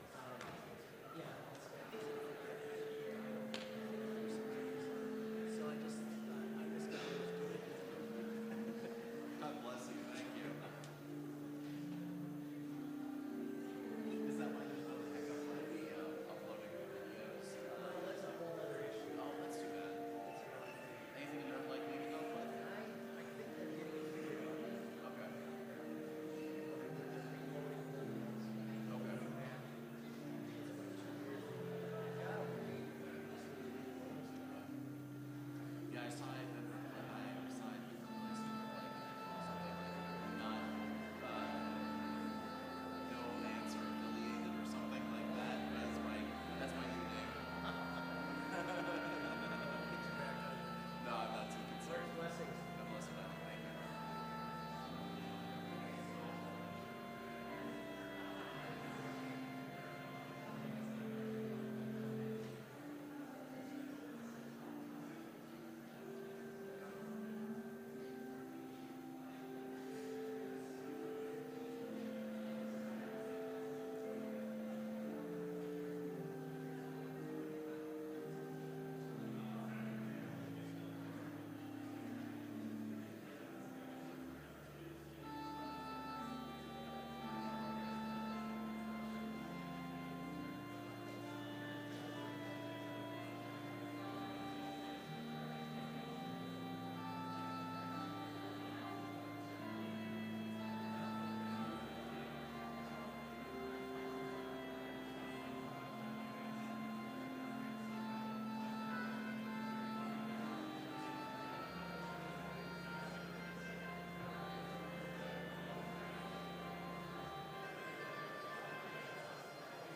Complete service audio for Chapel - October 15, 2019